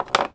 R - Foley 231.wav